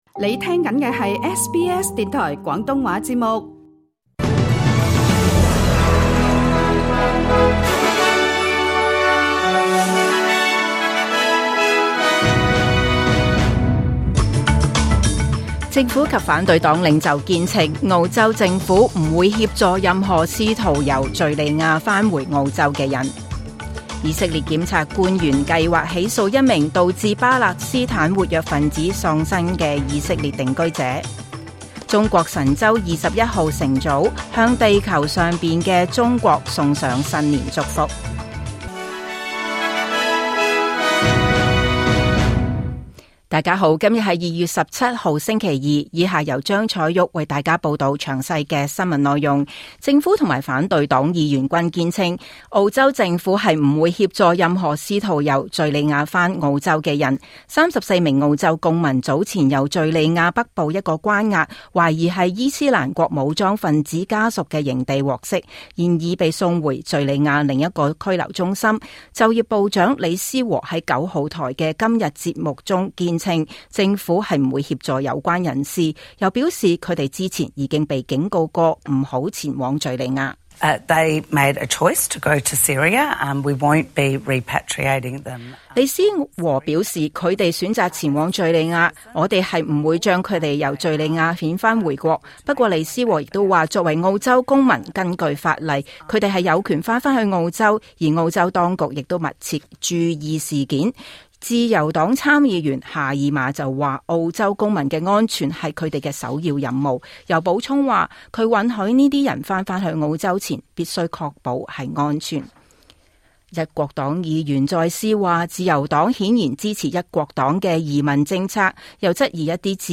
2026 年 2 月17 日 SBS 廣東話節目詳盡早晨新聞報道。